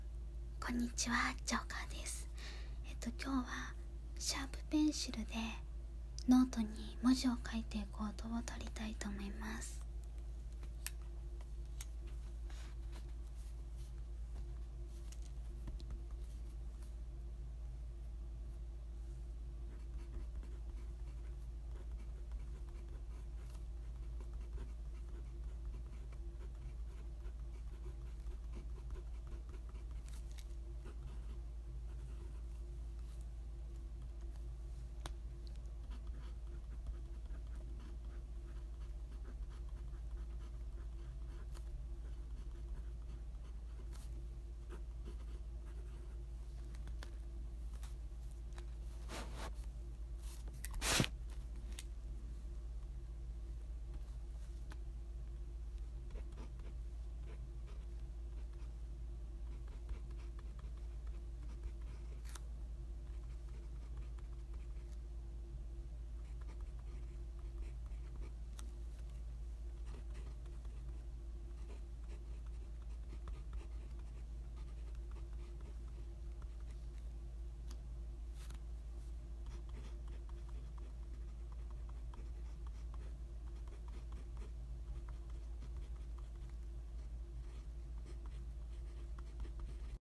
音フェチ★文字を書く